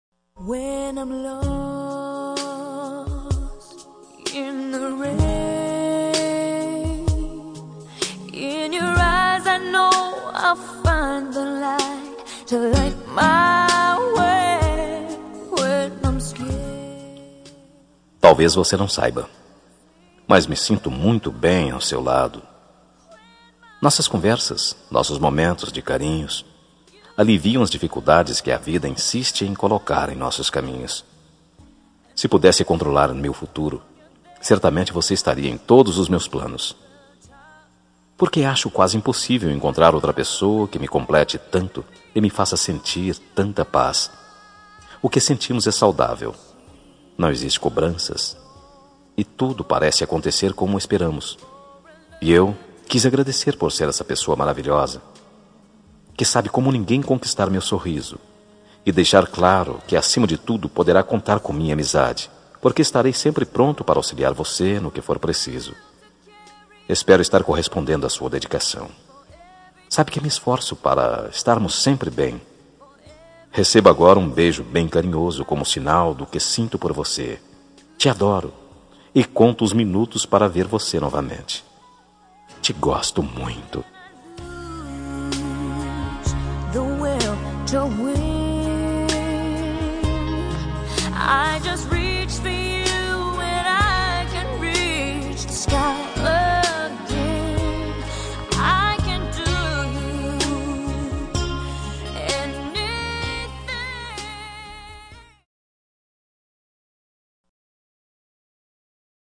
Telemensagem Início de Namoro – Voz Masculina – Cód: 759